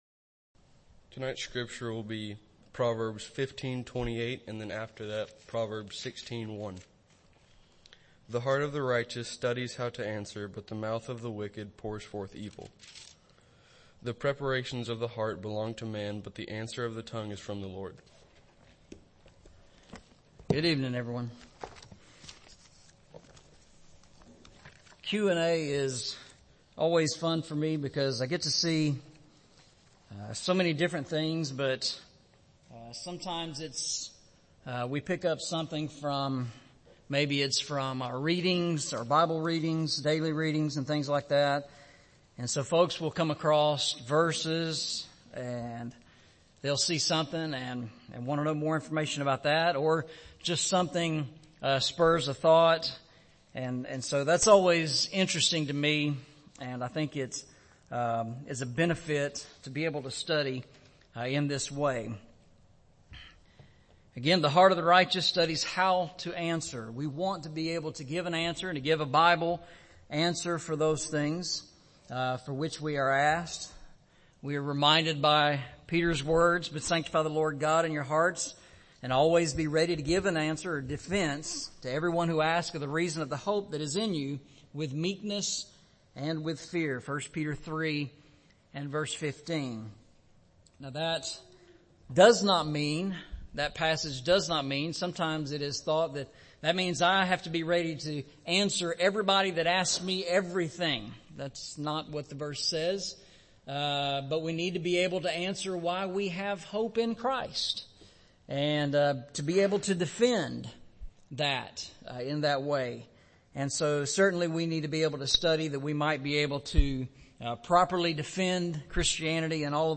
Series: Eastside Sermons
Eastside Sermons Service Type: Sunday Evening Preacher